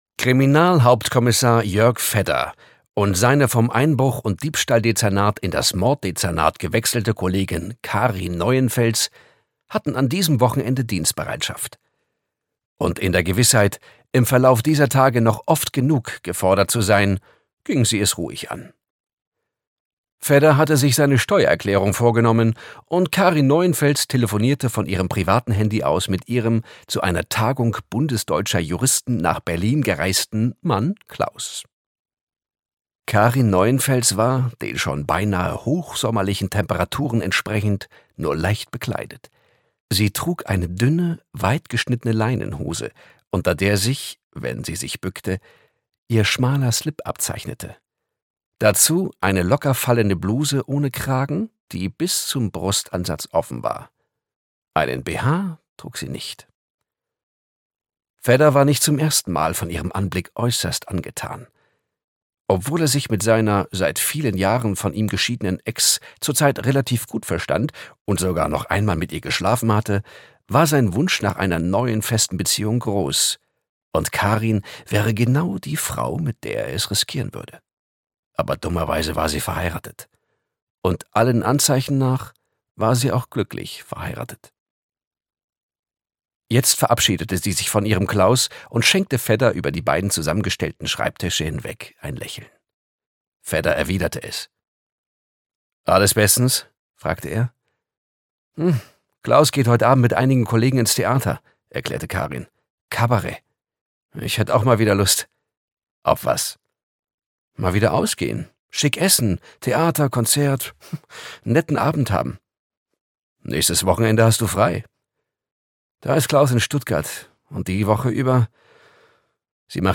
Krimi to go: Der letzte Freier - Frank Göhre - Hörbuch